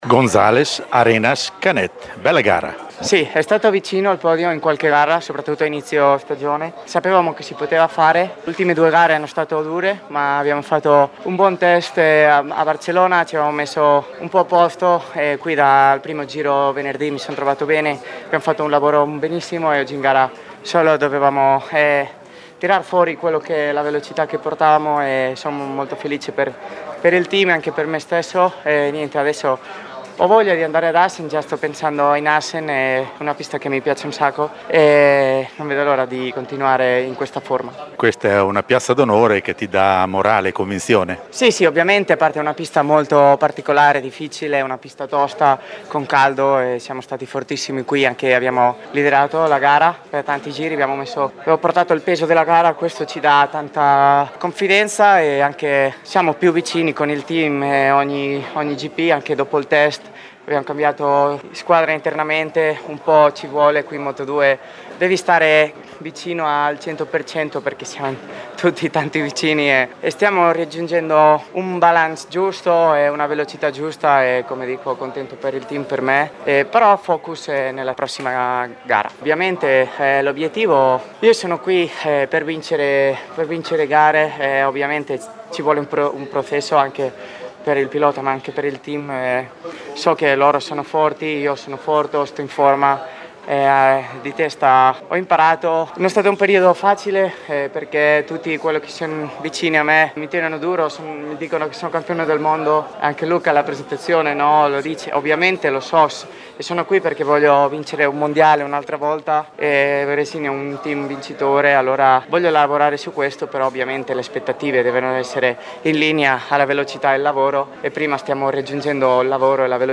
Albert Arenas intervistato